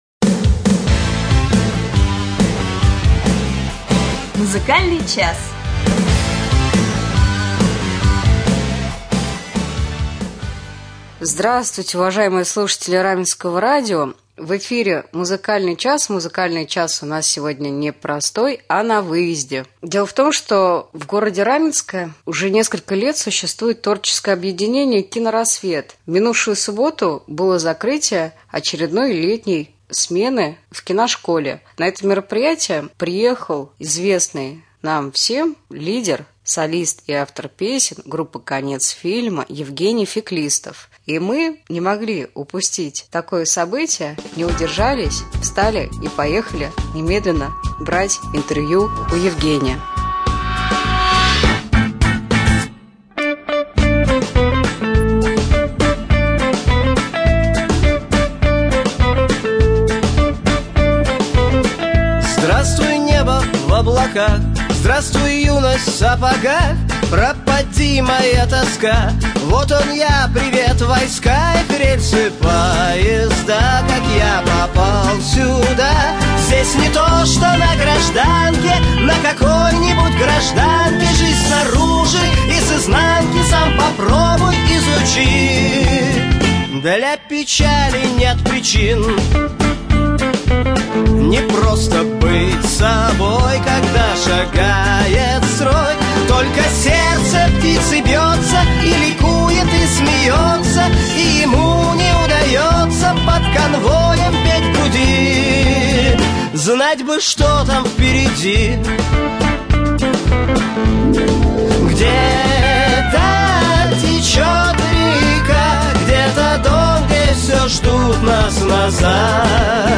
Интервью с солистом и лидером группы «Конец фильма» Евгением Феклистовым
Сегодня в 12.00 состоится «Музчас» на Раменском радио, в котором вы услышите интервью с солистом и лидером группы «Конец фильма» Евгением Феклистовым.